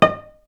vc_pz-D#5-ff.AIF